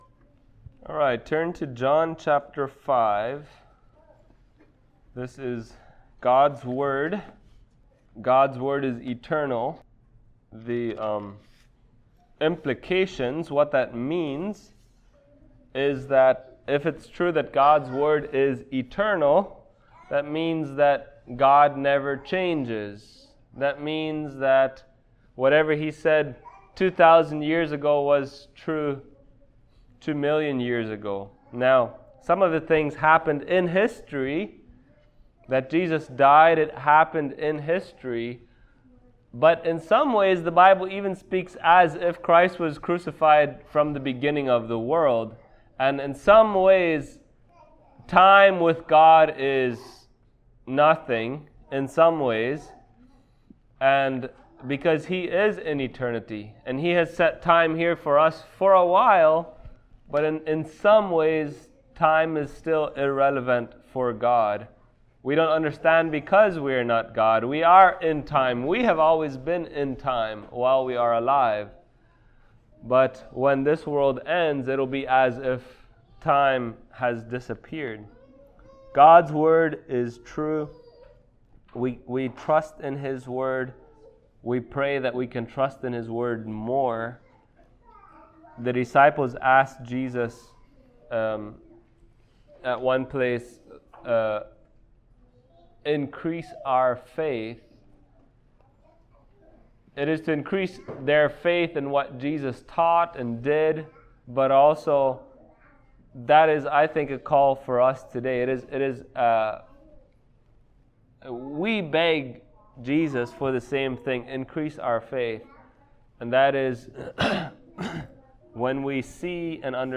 John Passage: John 5:31-37 Service Type: Sunday Morning Topics